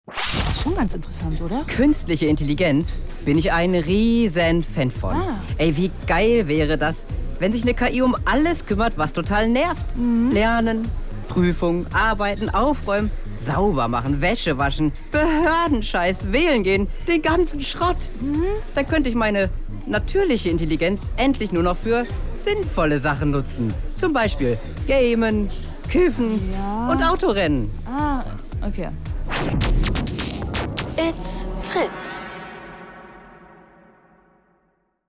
Künstliche Intelligenz | Fritz Sound Meme Jingle